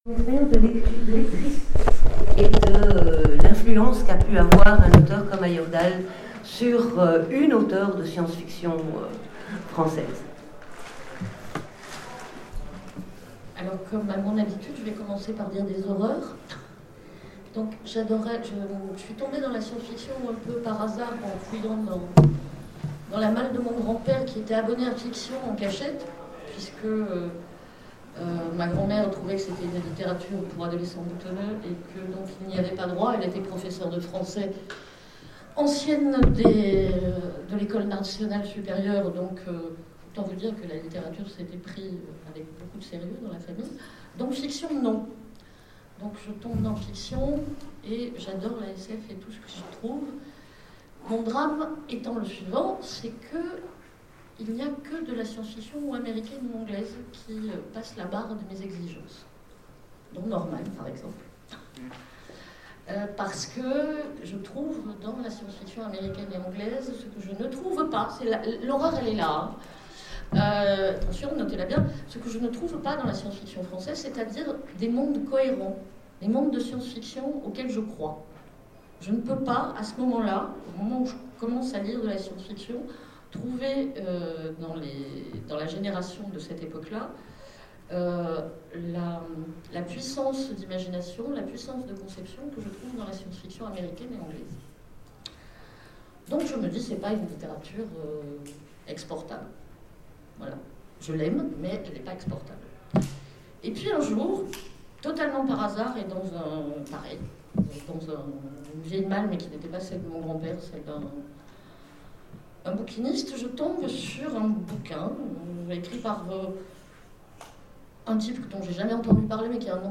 FMI 2016 : Conférence Hommage à Ayerdhal